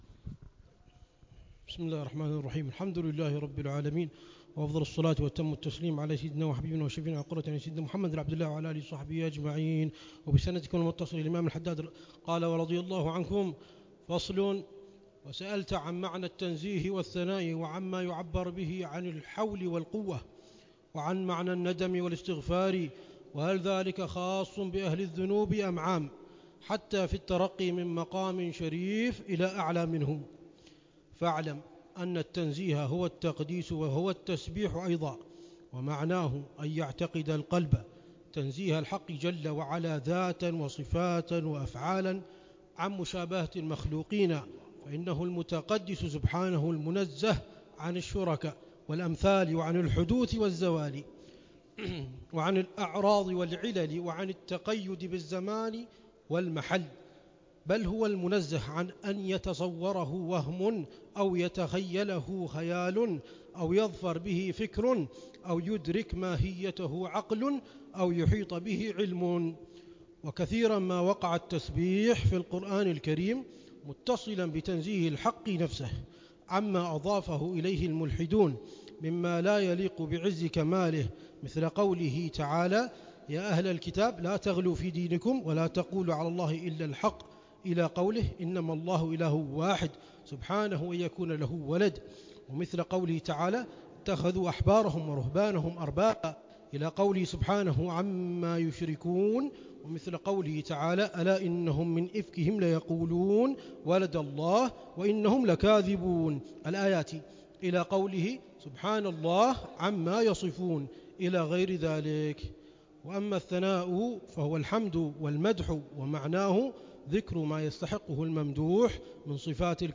شرح العلامة الحبيب عمر بن محمد بن حفيظ لكتاب إتحاف السائل بجواب المسائل، للإمام العلامة الحبيب عبد الله بن علوي الحداد رحمه الله، ضمن